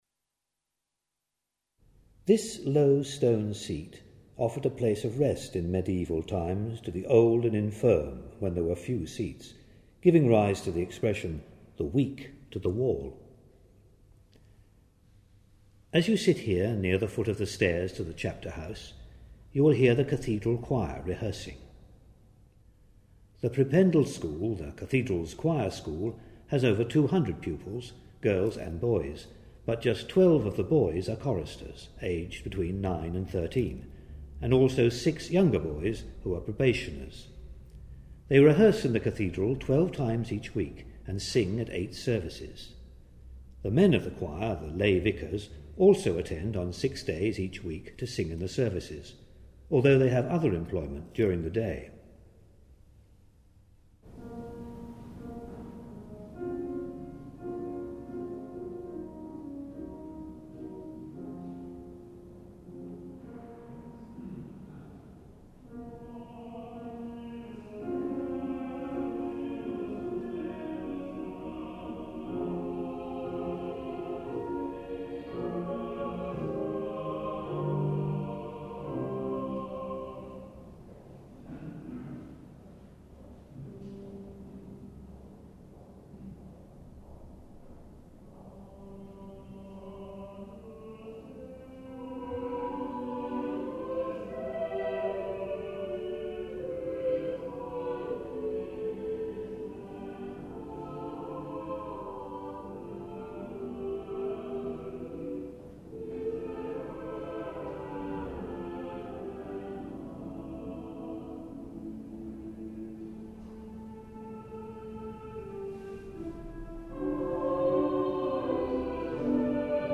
An Acoustic Fingerprint Guide of Chichester Cathedral - 3: South Transept with choir rehearsal and organ music, Trumpet Tune by John Stanley